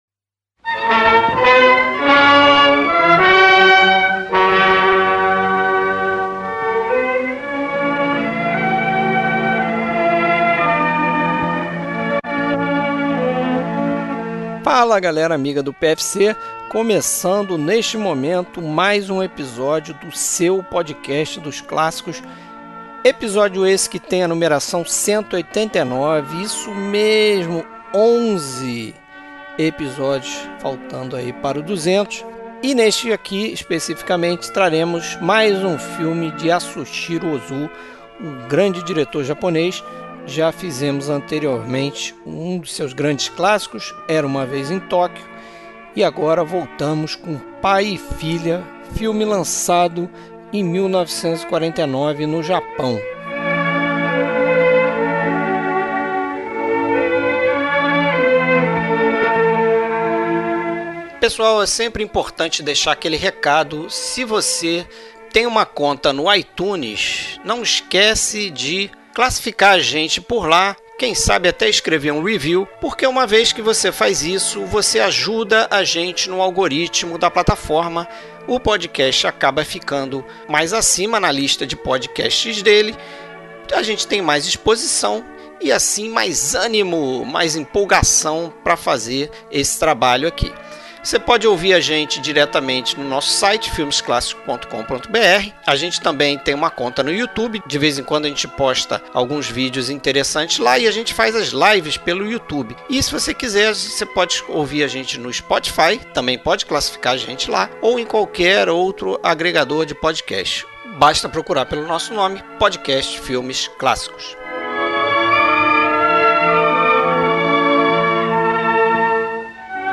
Trilha Sonora: Músicas compostas para este filme e outros do diretor.